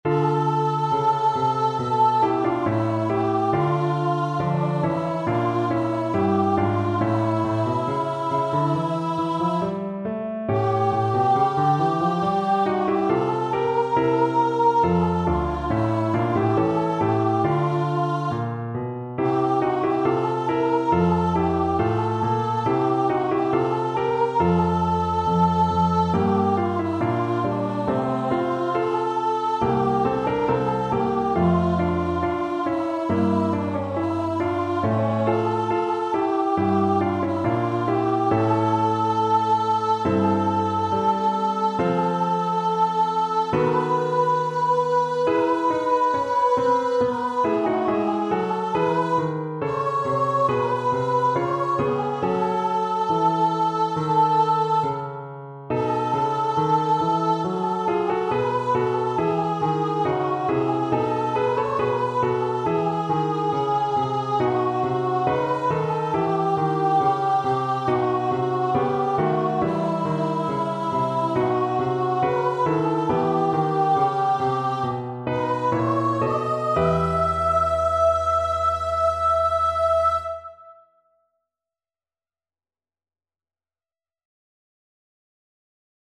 Voice
D minor (Sounding Pitch) (View more D minor Music for Voice )
Molto andante =c.69
National Anthems
puerto_rico_nat_VOICE.mp3